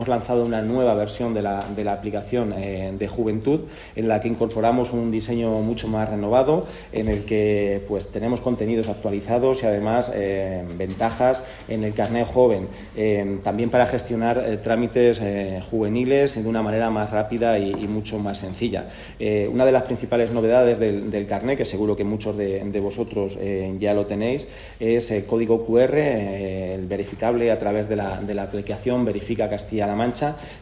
Consejería de Educación, Cultura y Deportes Jueves, 30 Octubre 2025 - 1:00pm El director general de Juventud y Deportes, Carlos Yuste, en la presentación de la IV Edición de Toledo Matsuri, que ha tenido lugar en el Castillo de San Servando de la capital regional, ha señalado que la App de Juventud del Gobierno de Castilla-La Mancha renueva su diseño e incorpora nuevas funcionalidades para facilitar el acceso a servicios y ventajas. yuste_-_app_juventud.mp3 Descargar: Descargar